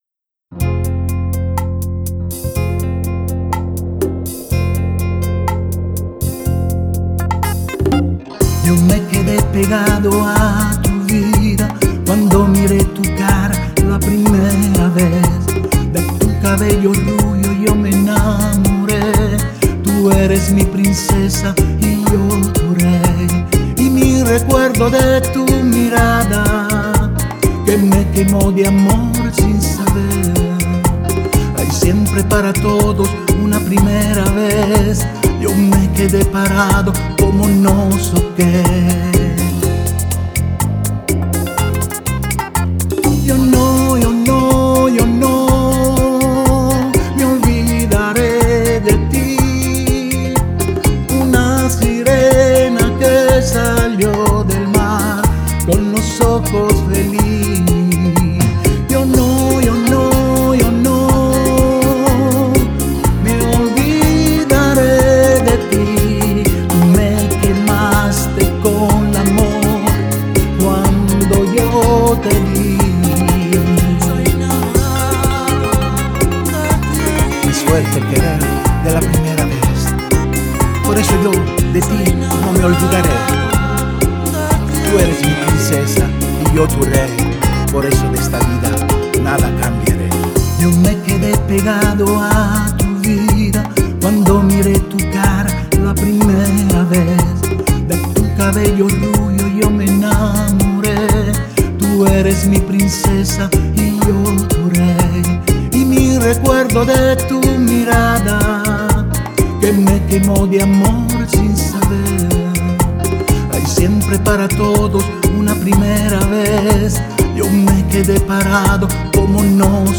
(Bachata)
una  Bachata romantica,  molto melodica e nostalgica.